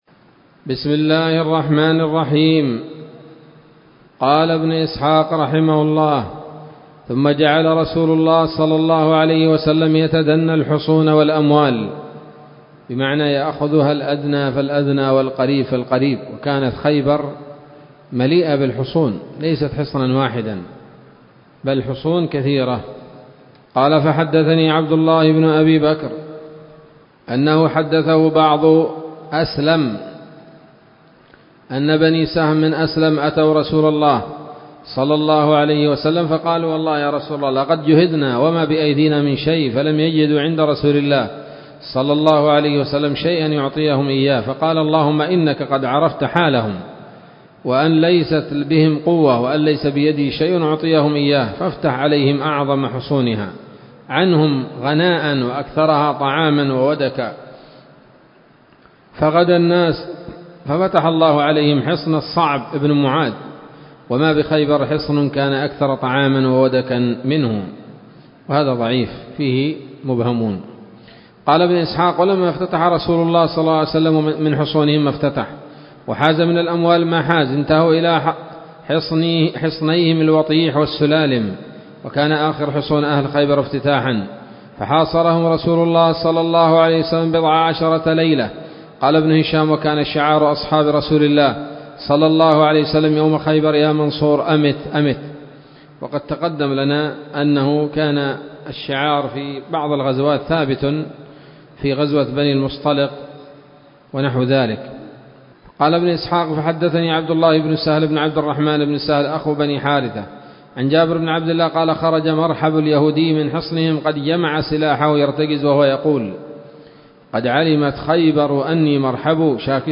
الدرس الأربعون بعد المائتين من التعليق على كتاب السيرة النبوية لابن هشام